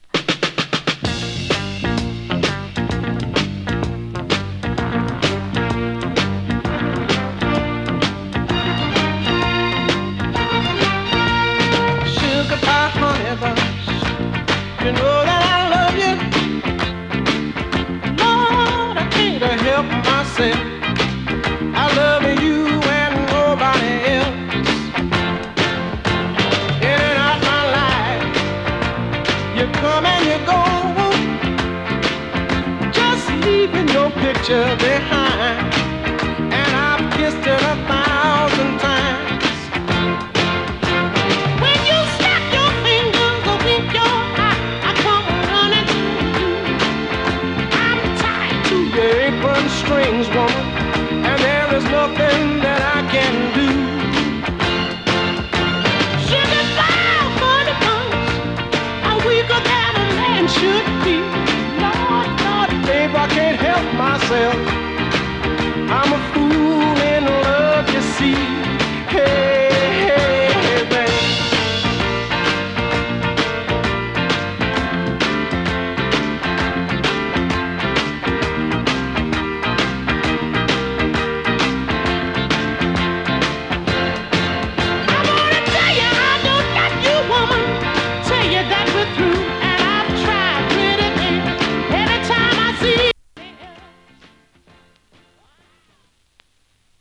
ノーザンソウル
＊音の薄い部分で稀に軽いチリパチ・ノイズ。